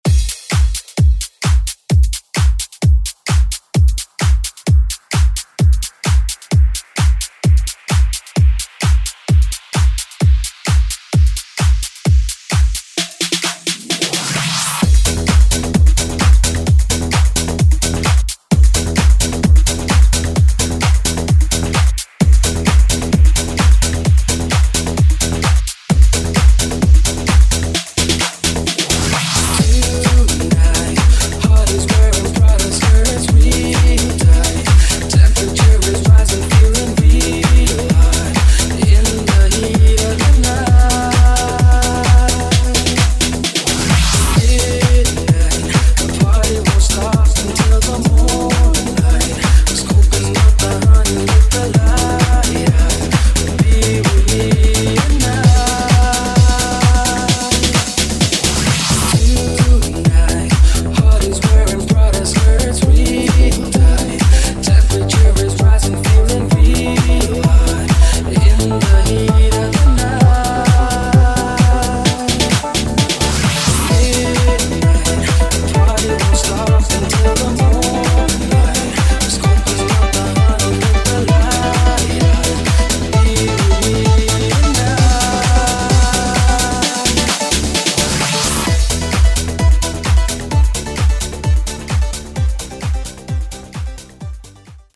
Genres: DANCE , RE-DRUM
Clean BPM: 128 Time